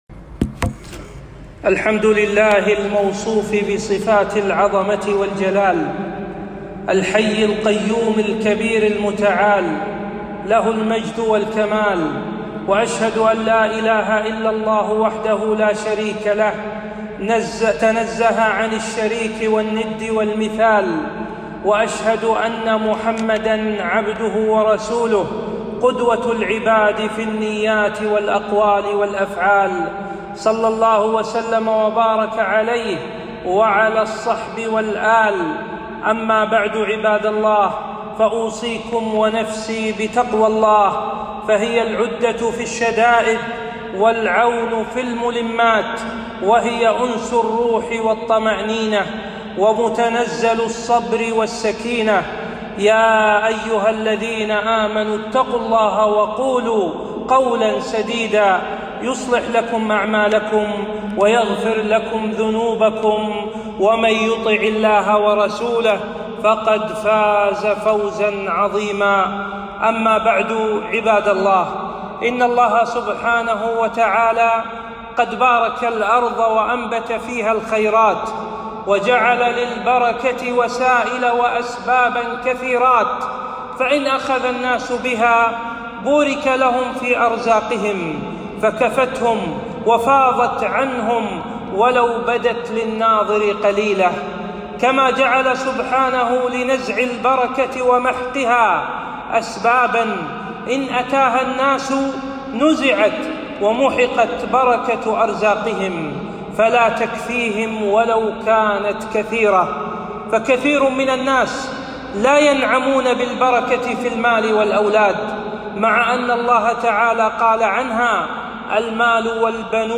خطبة - أسباب نزع البركة